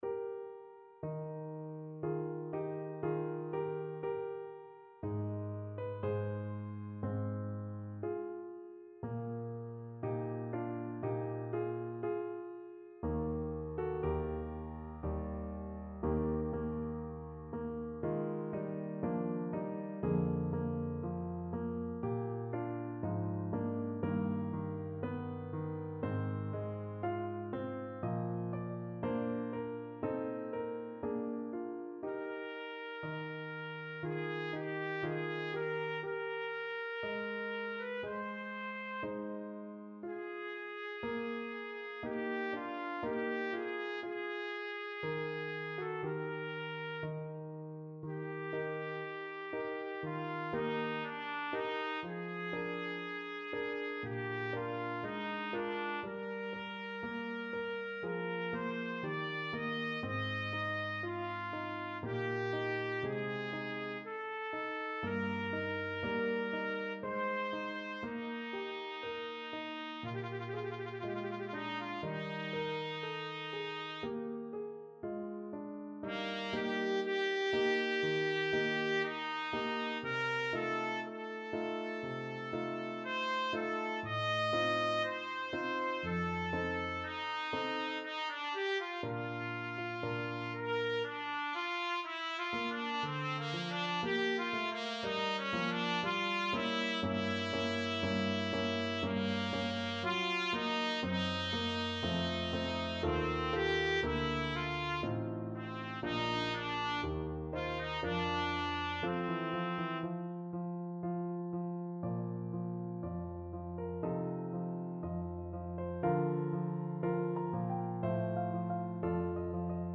Trumpet
4/4 (View more 4/4 Music)
Adagio = c. 60
Classical (View more Classical Trumpet Music)
mozartk261adagio_TPT.mp3